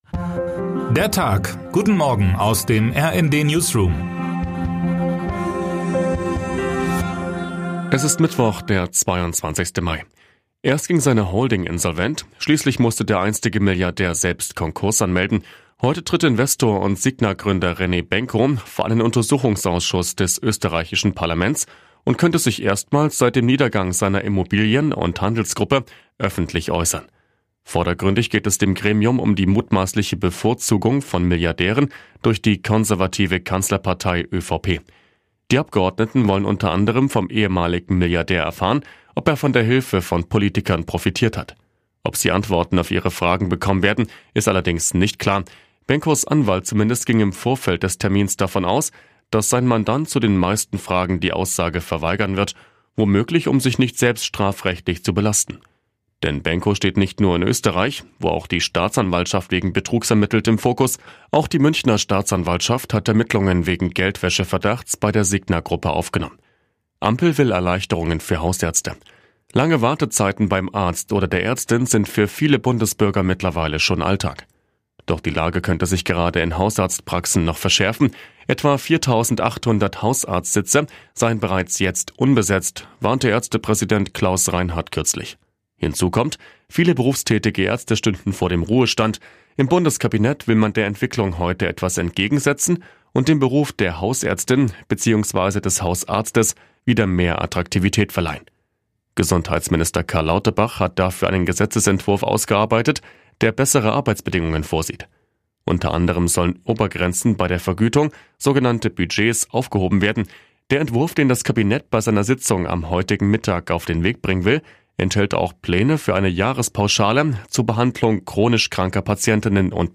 Guten Morgen aus dem RND-Newsroom
Nachrichten